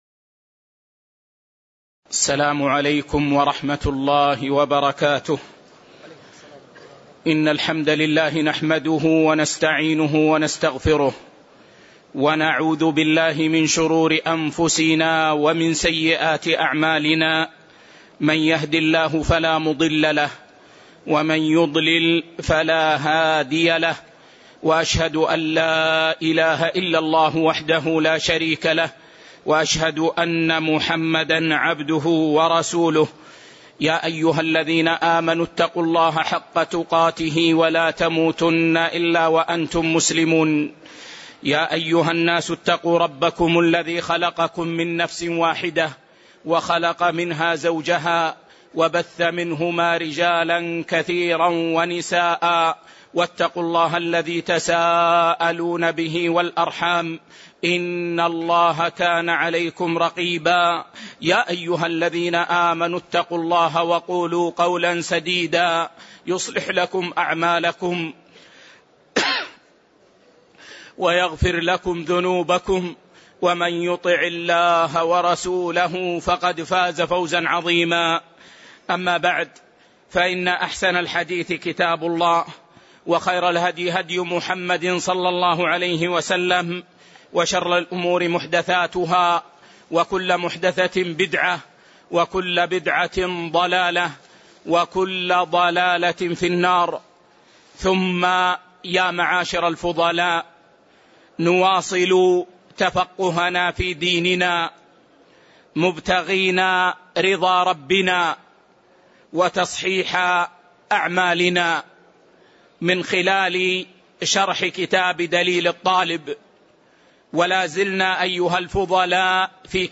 تاريخ النشر ٢٦ صفر ١٤٣٨ هـ المكان: المسجد النبوي الشيخ